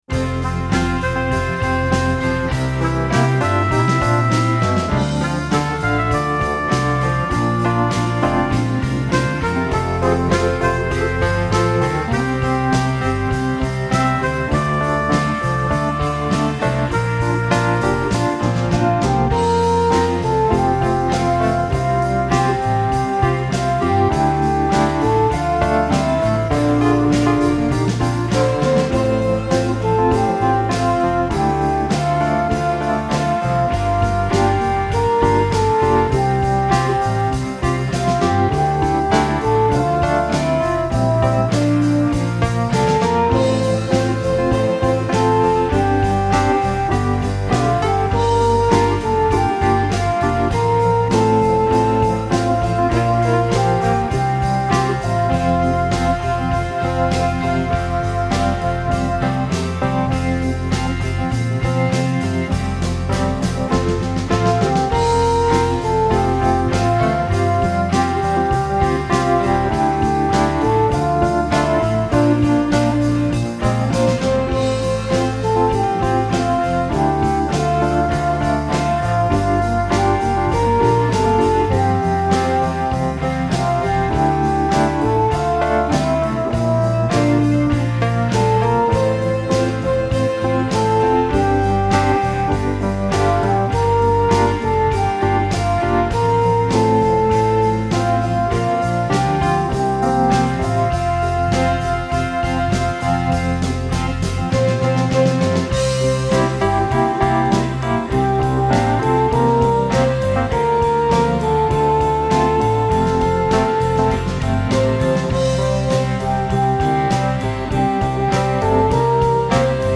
My backing is probably more raucous than it needs to be.